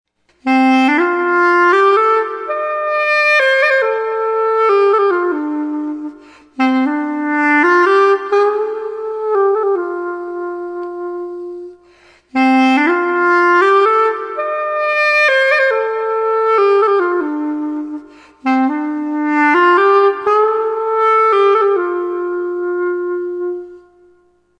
Зафун синий (Pocket Sax - Cobalt Blue)
Длина (см): 33
Зафун (xaphoon) - духовой музыкальный инструмент сочетающий в себе звучание саксофона, портативность и простоту в освоении. Звук извлекается при помощи саксофонной трости закрепленной на трубке с 9-ю игровыми отверстиями. Диапазон инструмента составляет две хроматические октавы.